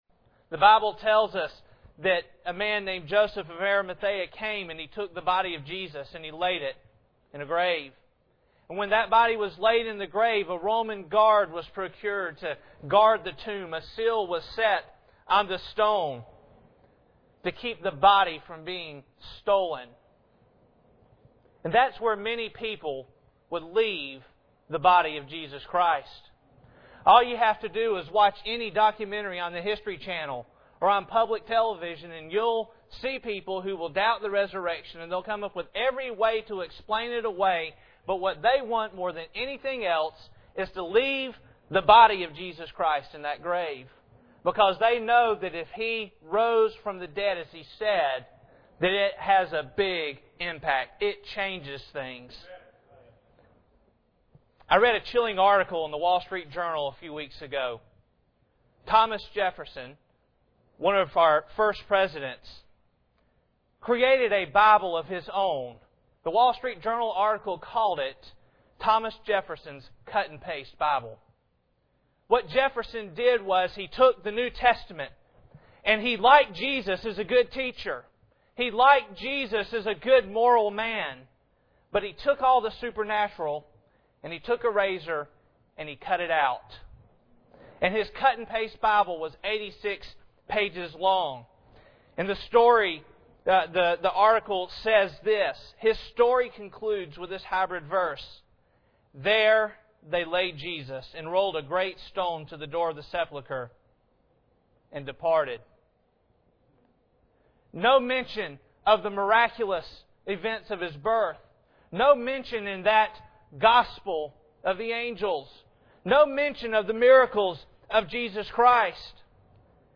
Matthew 27:57-7 Service Type: Sunday Morning Bible Text